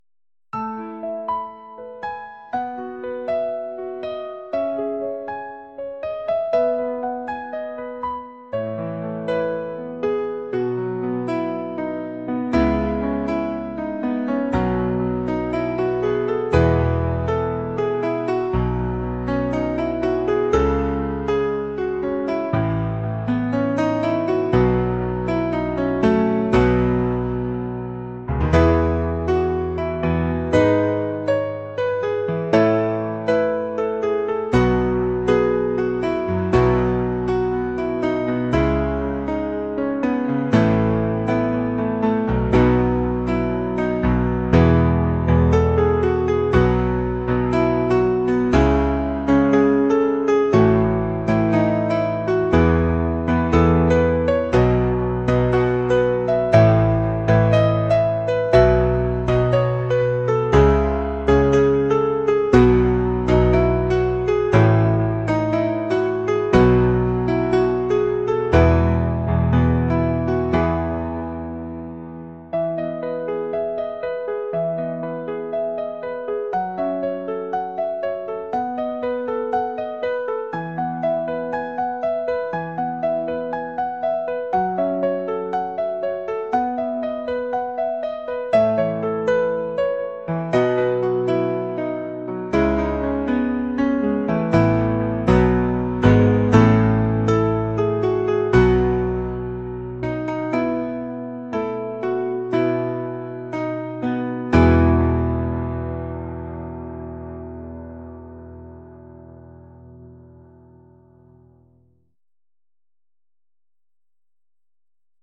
pop | cinematic | acoustic